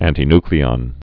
(ăntē-nklē-ŏn, -ny-, ăntī-)